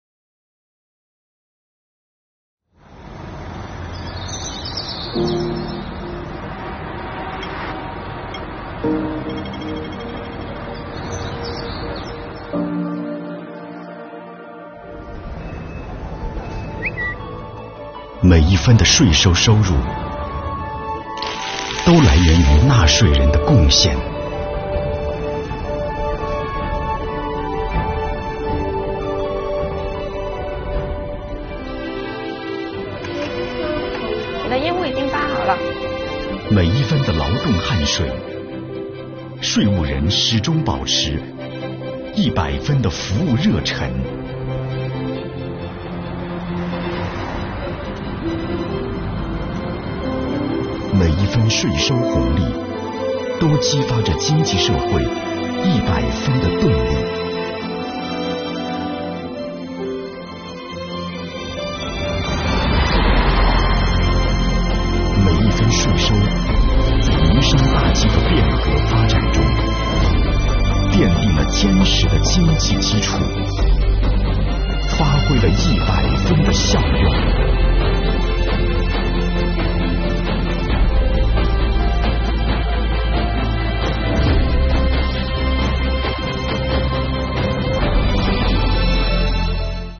作品制作精良，镜头语言十分丰富，运用了延时摄影、升格（慢镜头）摄影，固定镜头和运动镜头搭配合理，体现了专业的影视语言；加上节奏精准的剪辑，恰到好处的典型场景设计，昂扬激荡的音乐，展现了各行各业劳动者为社会主义现代化建设拼搏的可贵瞬间。